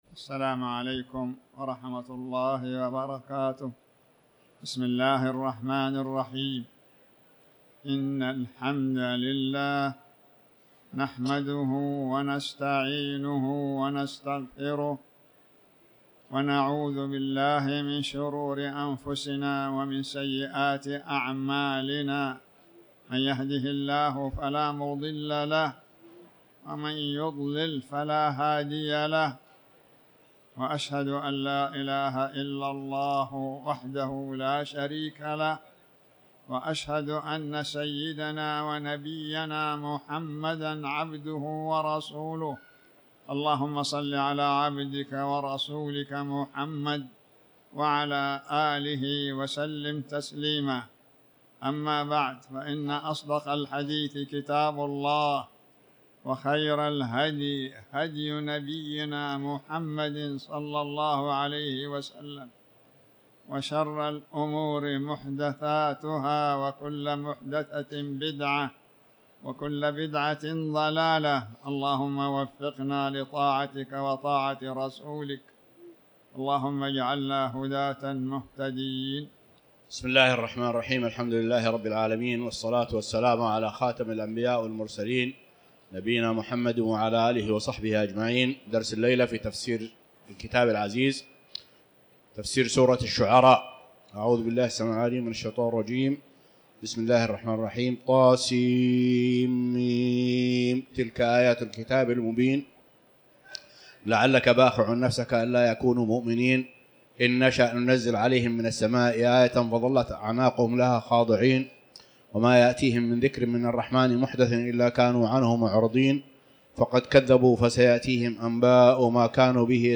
تاريخ النشر ٣٠ شوال ١٤٤٠ هـ المكان: المسجد الحرام الشيخ